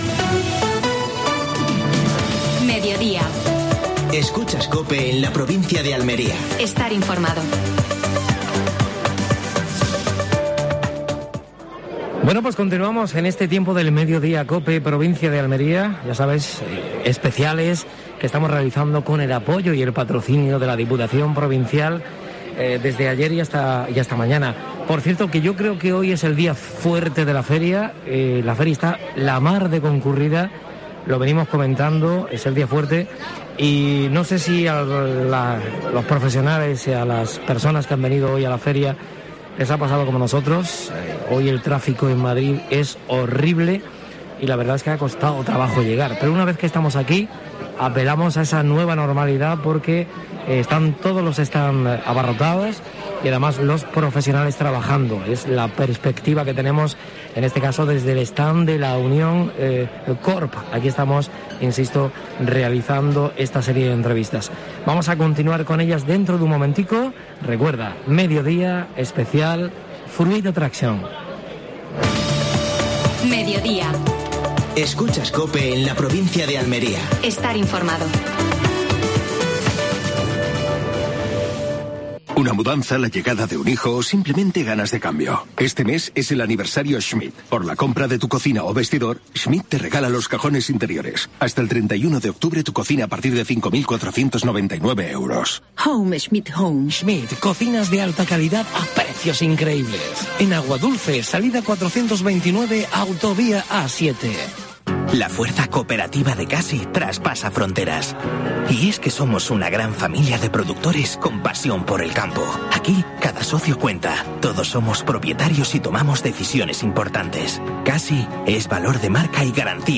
Actualidad en Fruit Attraction. Entrevista a Francisco Góngora (alcalde de El Ejido).